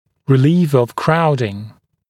[rɪ’liːf əv ‘kraudɪŋ][ри’ли:ф ов ‘краудин]уменьшение скученности, ослабление скученности, устранение скученности